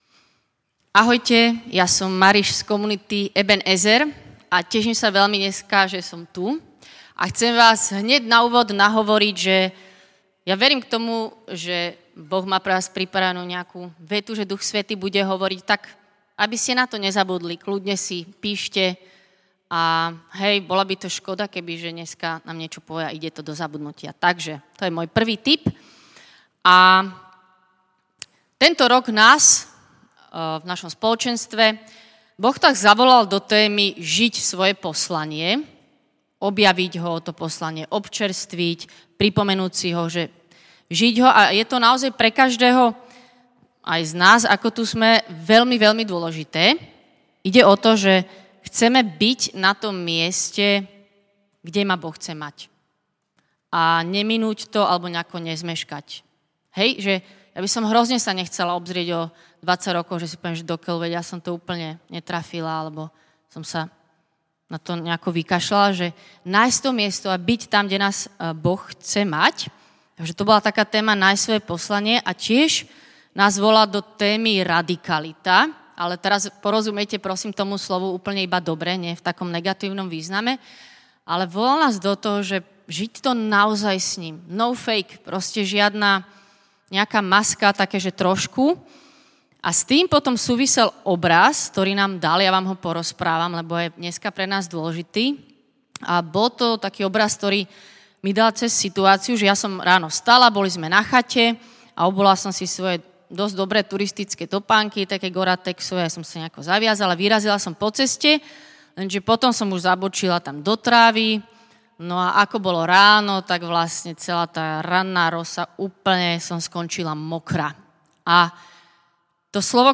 Slovo zaznelo na otvorenom stretnutí našeho spoločenstva v apríli 2024